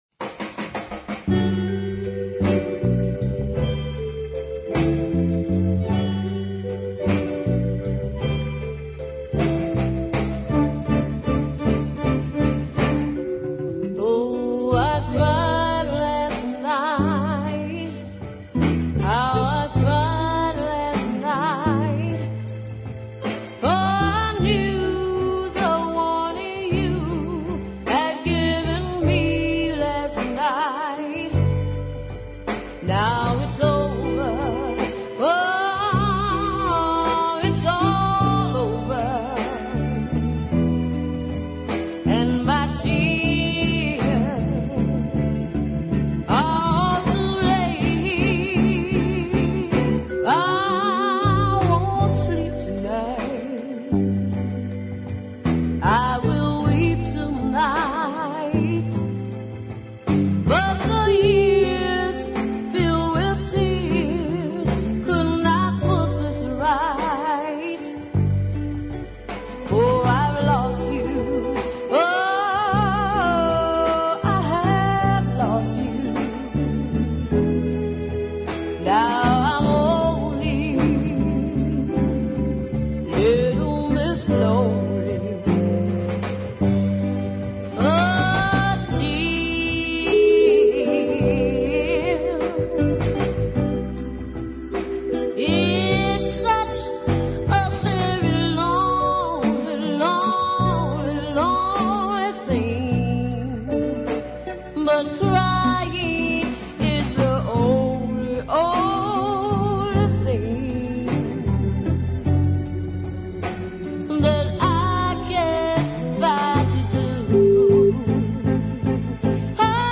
A less cluttered arrangement
rich fruity vocals